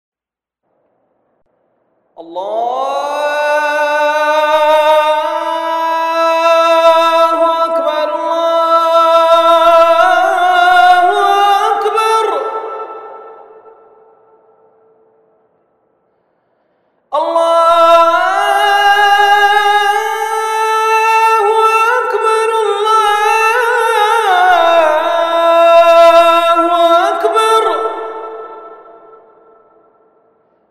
Презыв к малитве,длится 30секунд